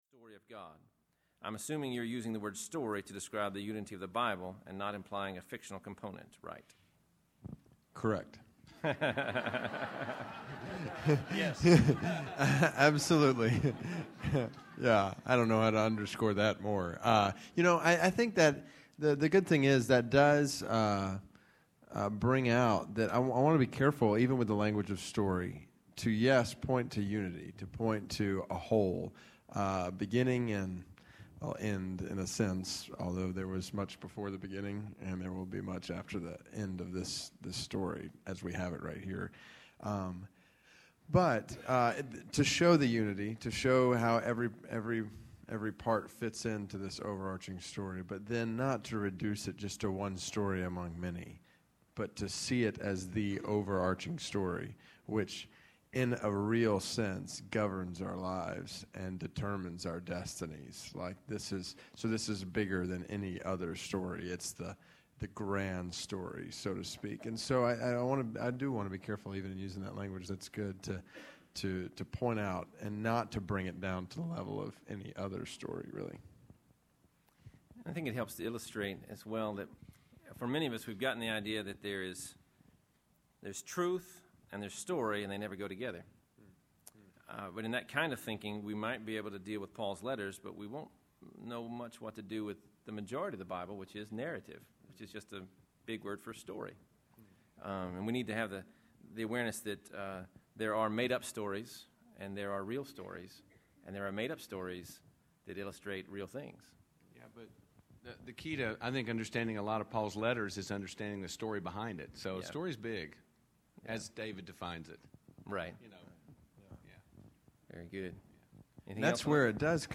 Read The Bible For Life Conference: Panel Discussion